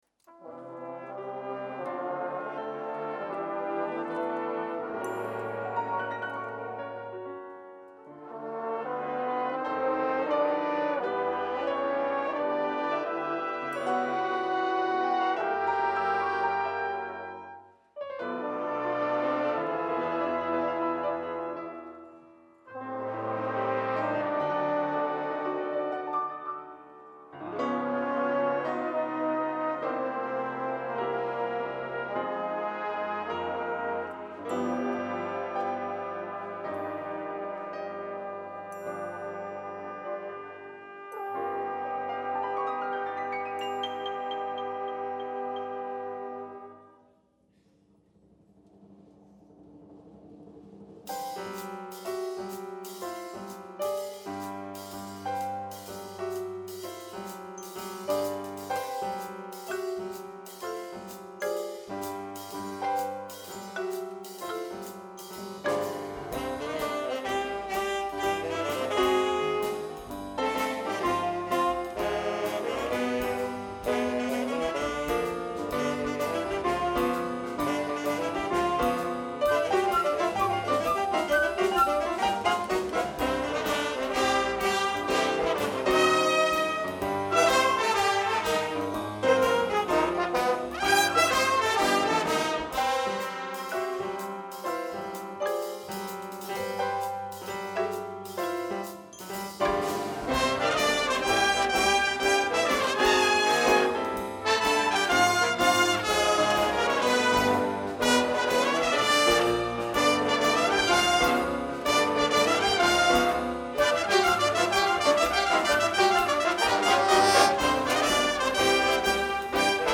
bateria
trompeta
piano
Contrabaix